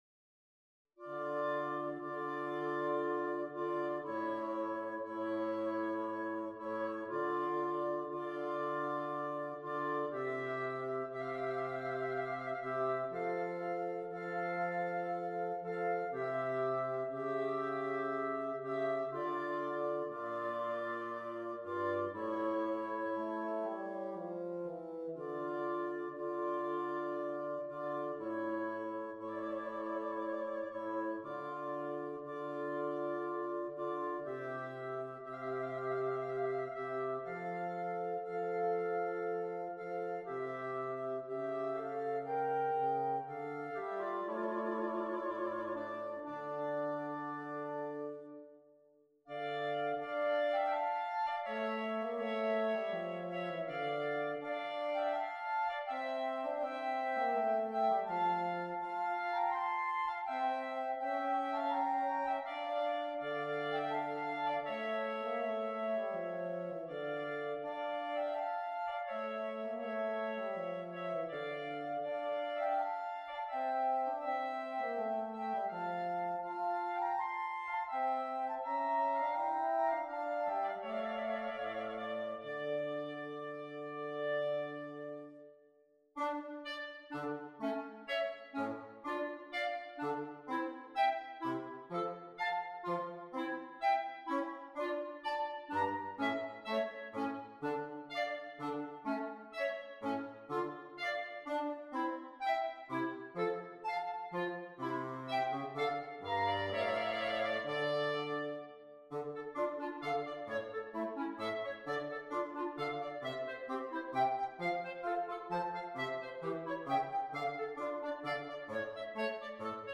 per 2 Clarinetti e Fagotto (o Clarinetto Basso)